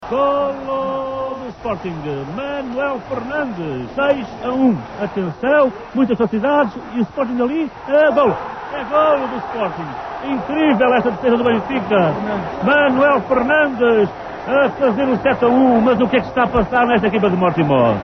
Explicação As águias chegam a Alvalade com uma vantagem de seis pontos sobre o Sporting CP, mas os leões acabam por vencer a partida, com quatro golos de Manuel Fernandes. Rui Tovar relata a histórica goleada.